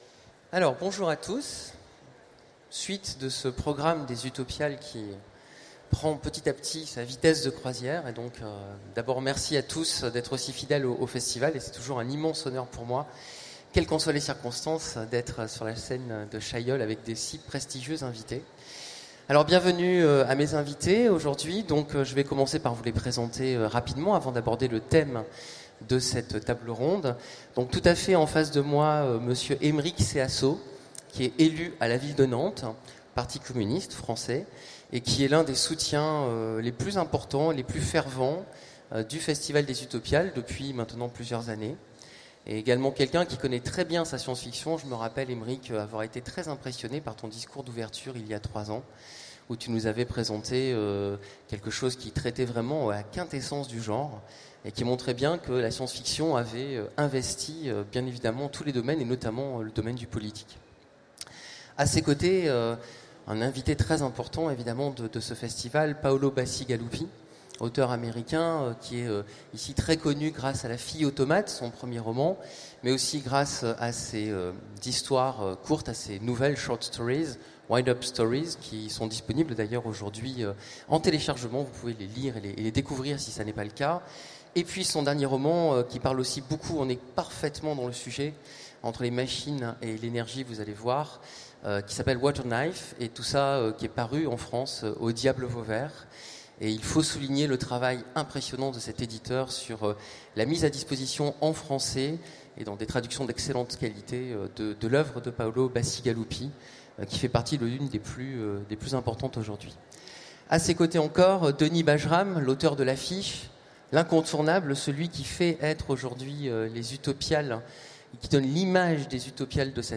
Utopiales 2016 : Conférence Qui aura accès aux machines et à leurs ressources ?